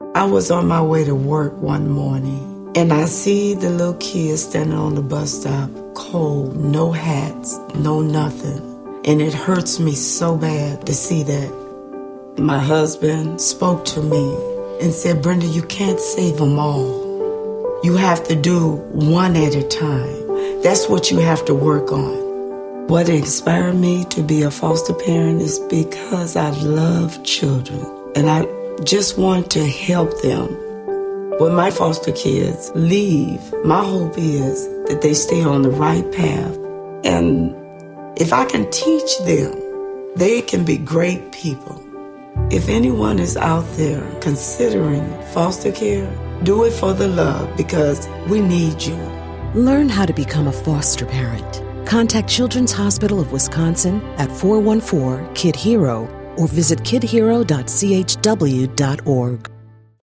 This spot was used to encourage people in the Milwaukee area to become foster parents. I provided interview questions and edited together this spot from roughly 30 minutes of interview audio.